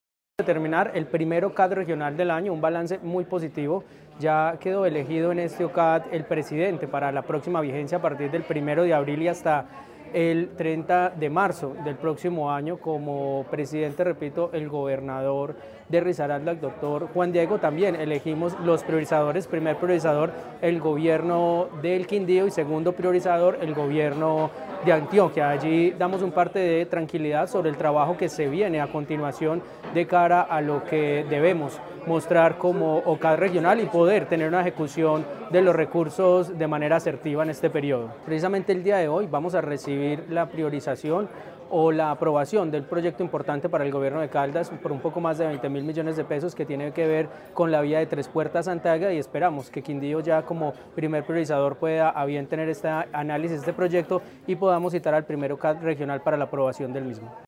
Ronald Bonilla, gobernador (e) de Caldas.